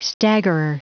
Prononciation du mot staggerer en anglais (fichier audio)
Prononciation du mot : staggerer